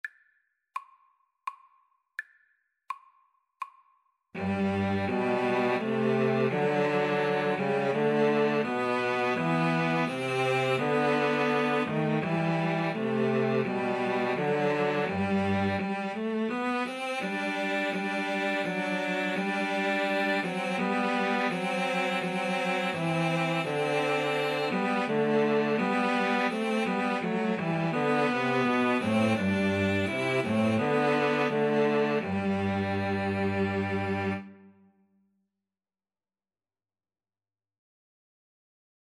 3/4 (View more 3/4 Music)
Cello Trio  (View more Easy Cello Trio Music)